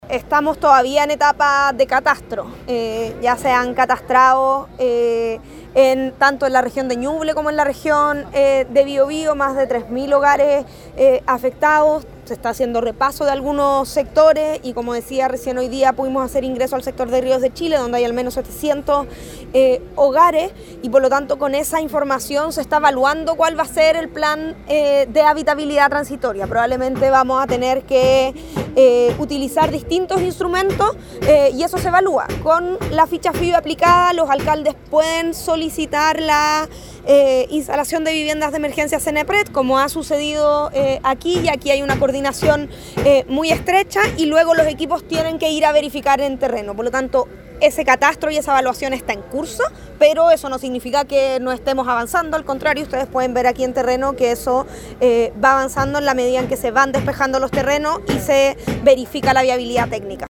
En esa misma línea, la ministra profundizó en que, en general, siguen en la etapa de catastro, considerando que hay barrios como “Ríos de Chile” en Penco, donde recién hoy se pudo ingresar a aplicar las Fichas de Emergencia, producto del trabajo previo encabezado por el Servicio Médico Legal.